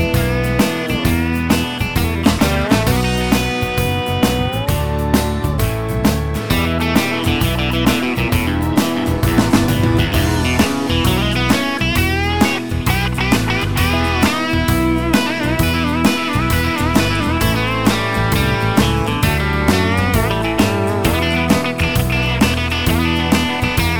no Backing Vocals Country (Male) 5:06 Buy £1.50